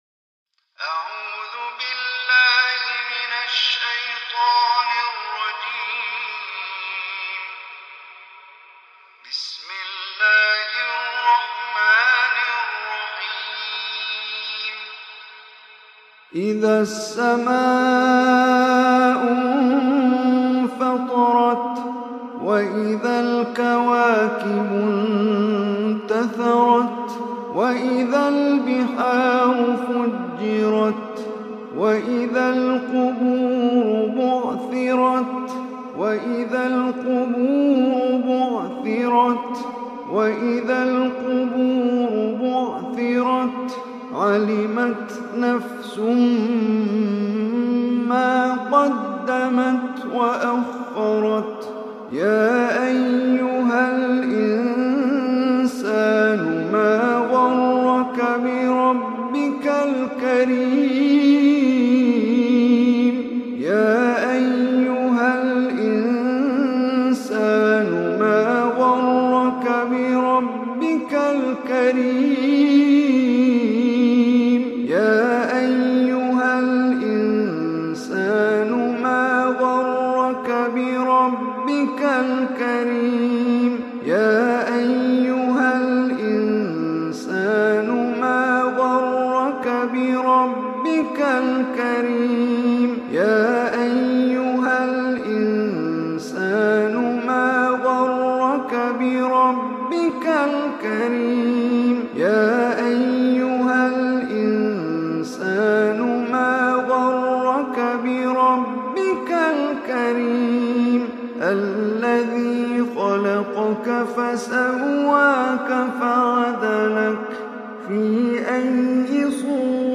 Surah Infitar MP3 Recitation